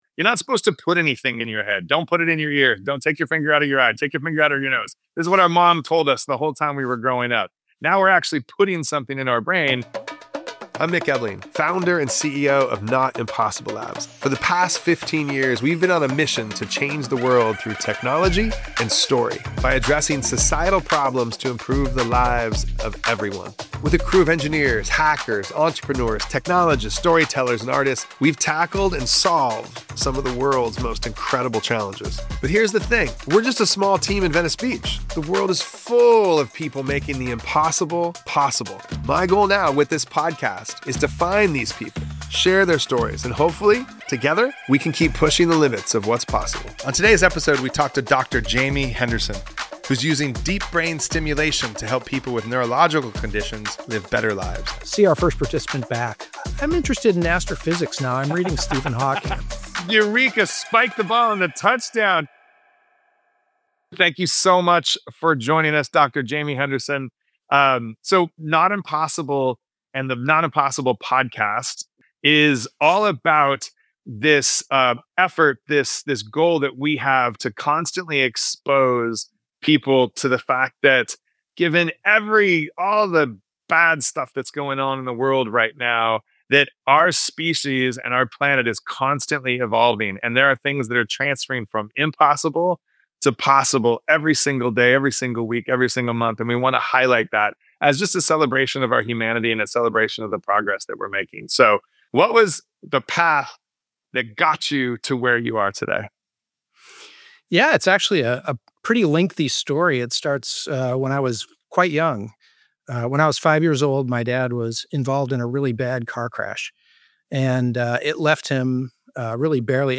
This conversation explores how the brain learns, adapts, remembers, and sometimes rebuilds pathways once thought gone forever.